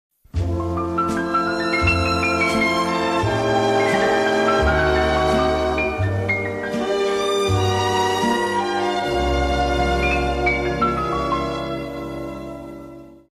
The Perfect Ferrari sound effects free download